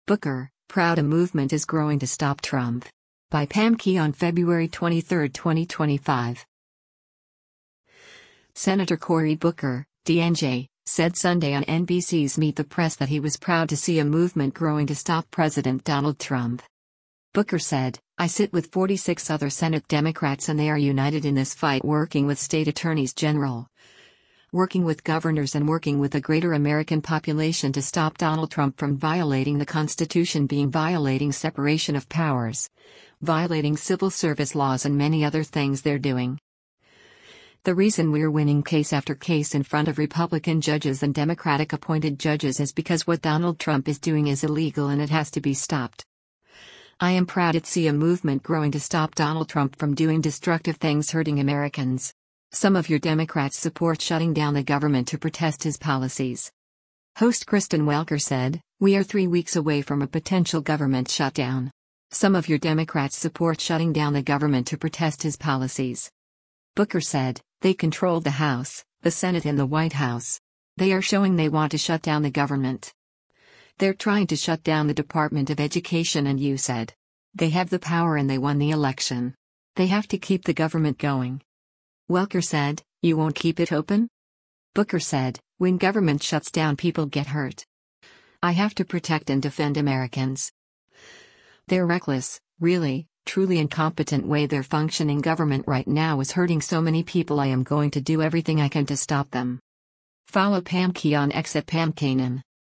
Senator Cory Booker (D-NJ) said Sunday on NBC’s “Meet the Press” that he was “proud” to see a movement growing to stop President Donald Trump.